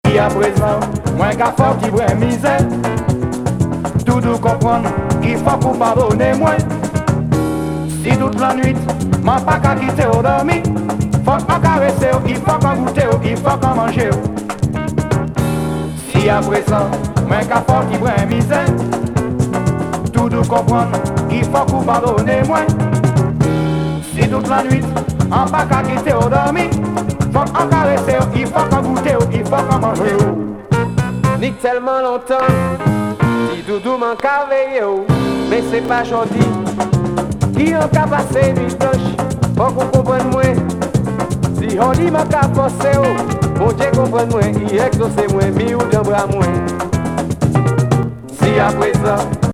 フレンチ・カリビアン極上アルバム!